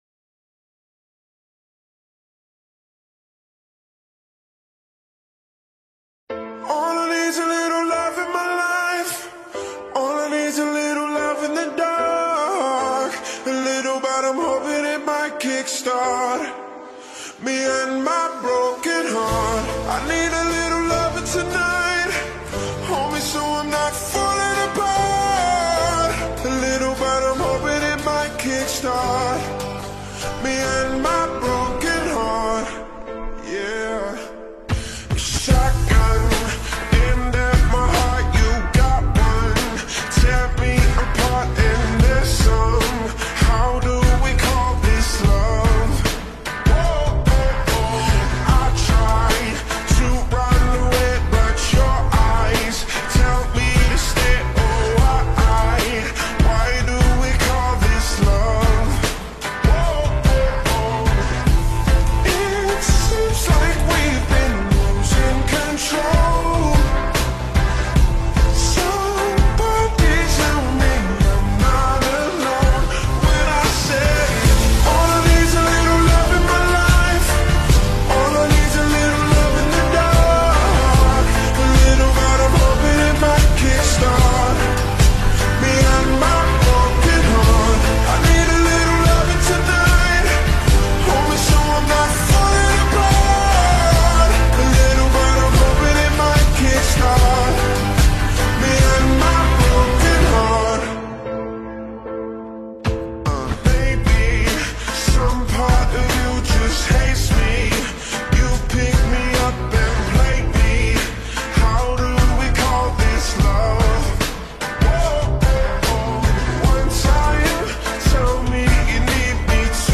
ورژن آهسته کاهش سرعت